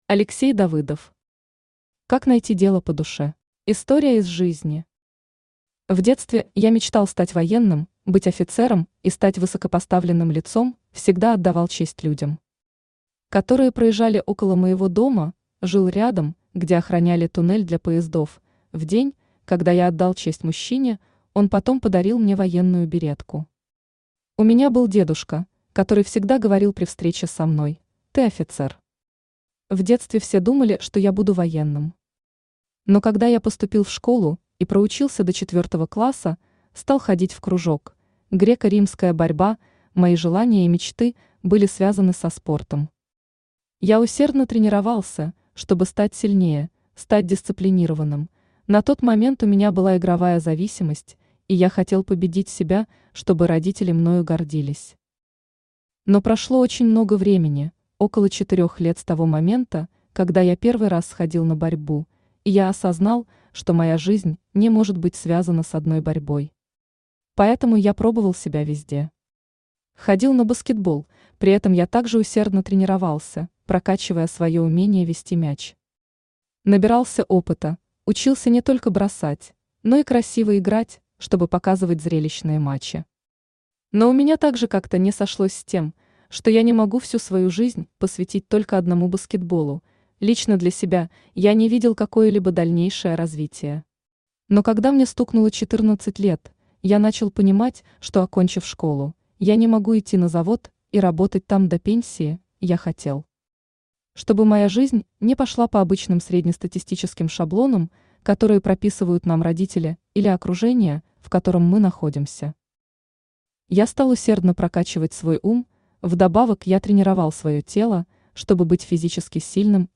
Aудиокнига Как найти дело по душе Автор Алексей Владимирович Давыдов Читает аудиокнигу Авточтец ЛитРес.